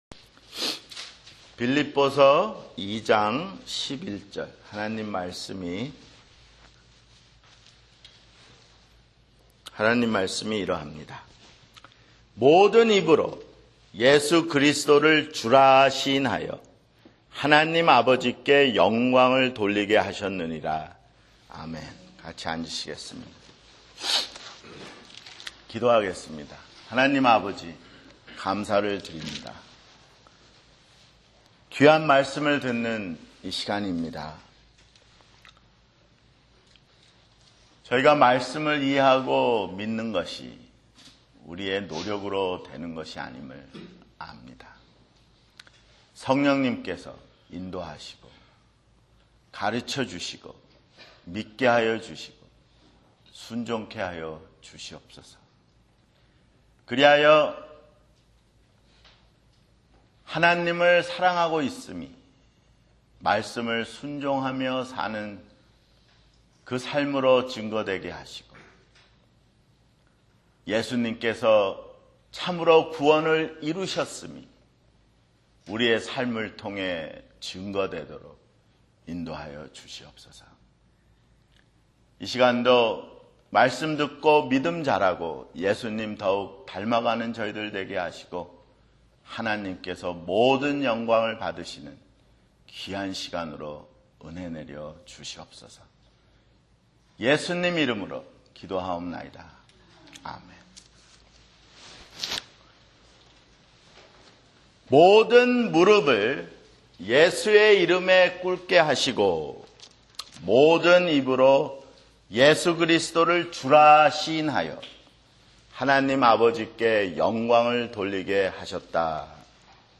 [주일설교] 빌립보서 (29)